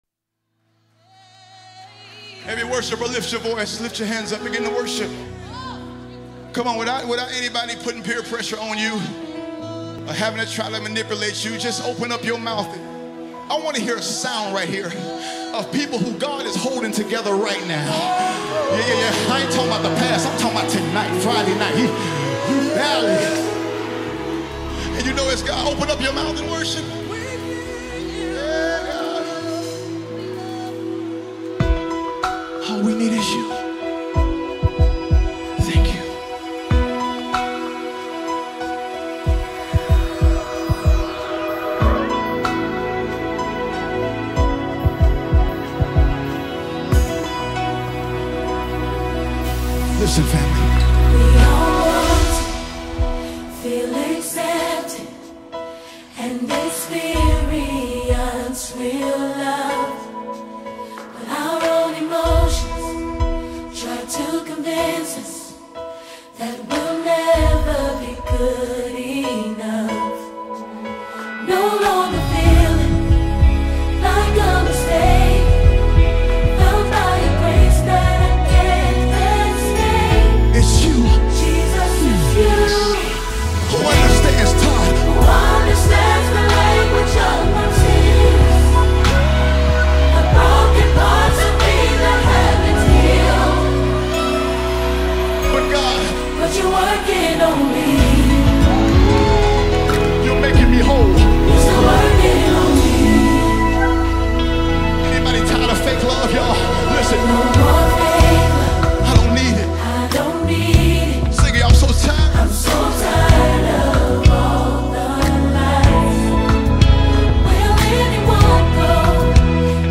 Mp3 Gospel Songs
America’s top-rated gospel singer